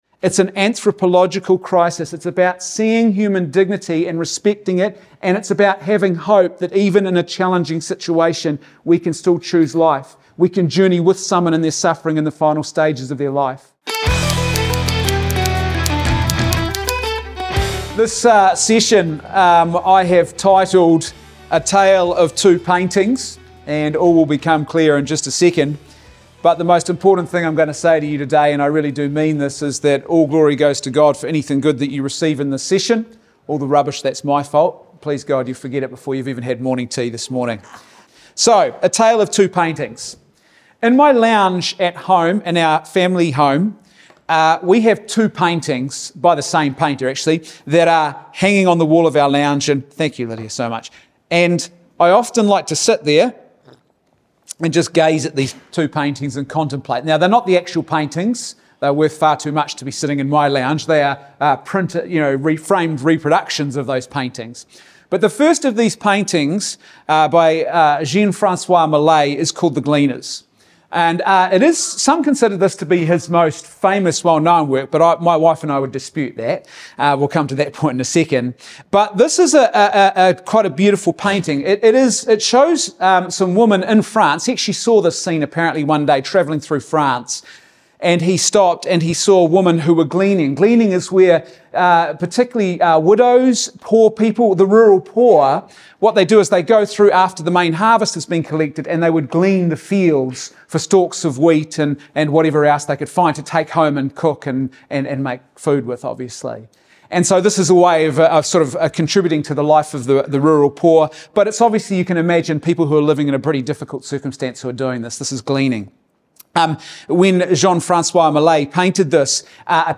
Today's episode features my presentation from last week's Voice For Life national conference about two important paintings that hang in our home, and offer profound meaning about the culture of death we find ourselves living in, and the counterculture of life which offers the true path to human flourishing.